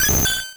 Cri de Mélodelfe dans Pokémon Rouge et Bleu.